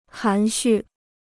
含蓄 (hán xù) Dicionário de Chinês gratuito